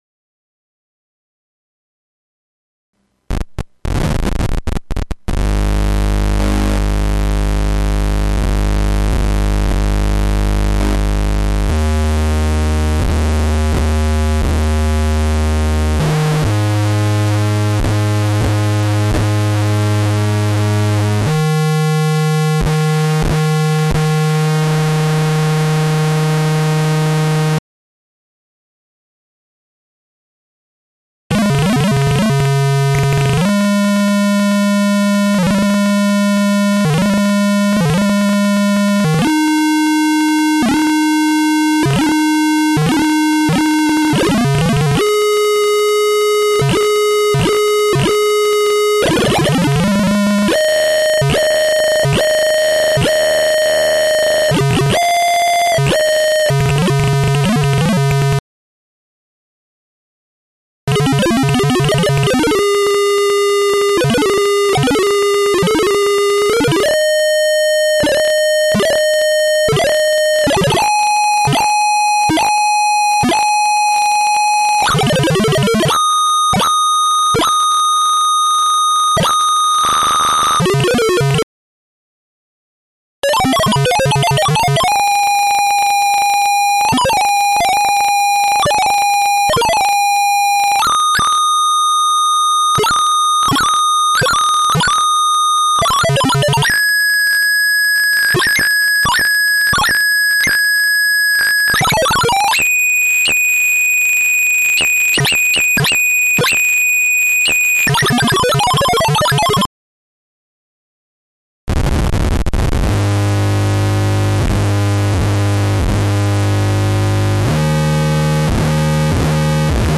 It's split in several parts. In between there are short breaks. In each parts I play always the same notes (on bass): low A, 5th (low E on guitar), octave, 5th above octave. Each tone is plucked once, and then after a while several times in a row. The signal goes either from the 4040 divider in case of the PLL, or the last comparator in case of the doublers (via a cap) into my USB soundcard.
* 12 db bandpass, schmidt trigger, both flip flops into PLL : 2 ocatves up
* 12 db bandpass into 3 doublers in series, 1 ocatve up
* 6 db bandpass into 3 doublers in series, 1 ocatve up
Interestingly, there's also some kind of glitch in the first part.
sounds = Beee  Zarrrrrr !!!    :D